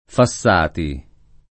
[ fa SS# ti ]